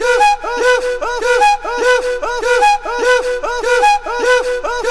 AFRIK FLUTE5.wav